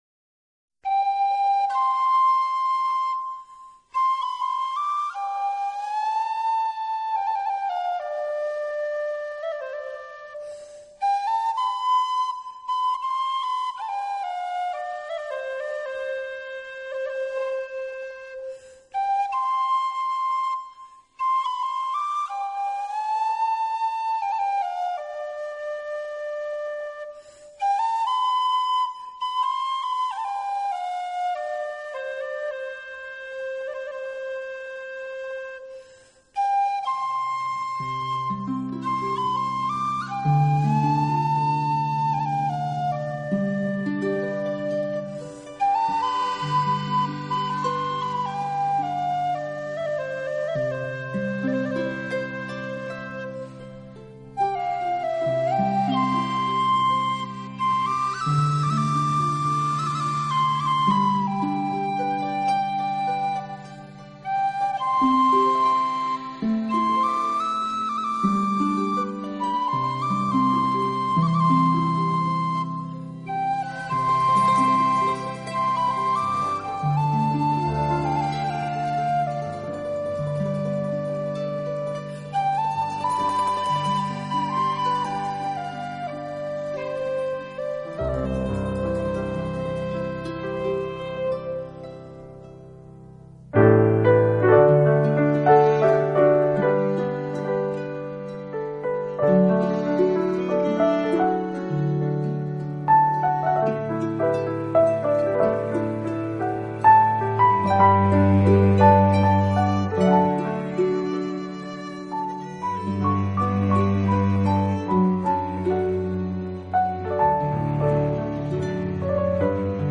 音乐类型：Newage/凯尔特
爱尔兰哨笛(Irish Whistle)